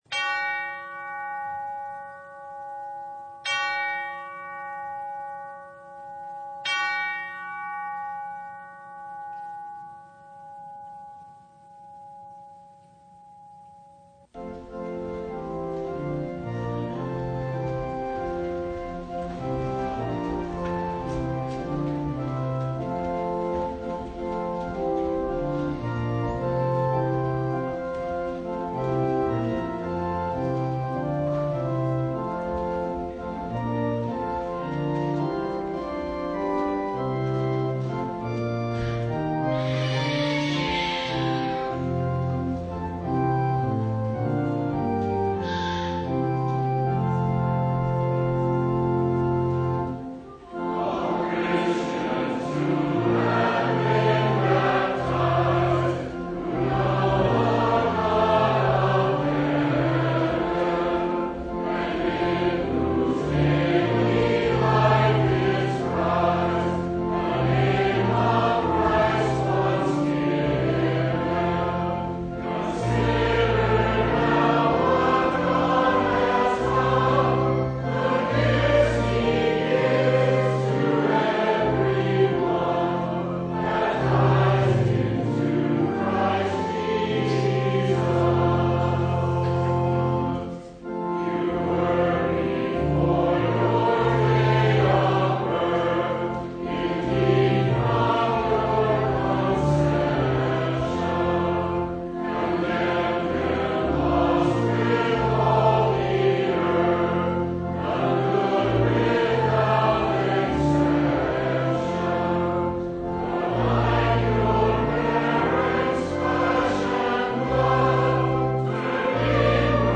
Download Files Notes Bulletin Topics: Full Service « The Gospel of Luke – Chapter 9 Forgive Us .